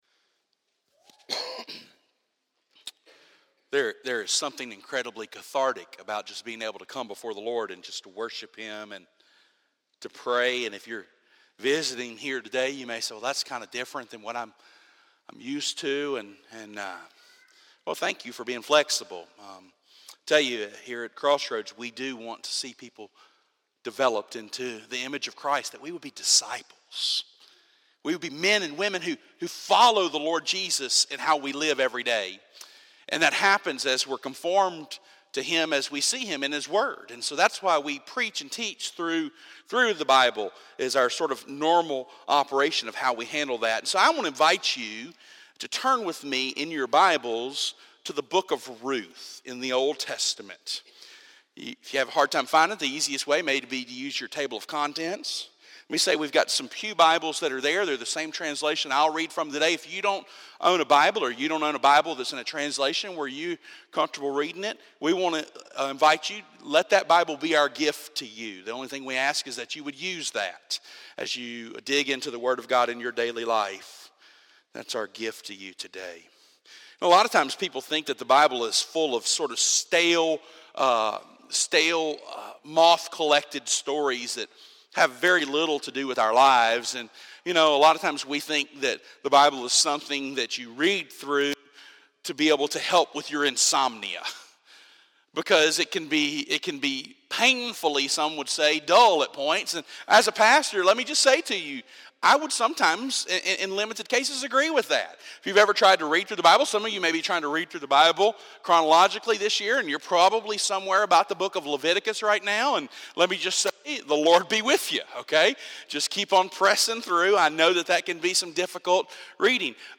A Story of Redemption and Hope Audio Sermon Save Audio https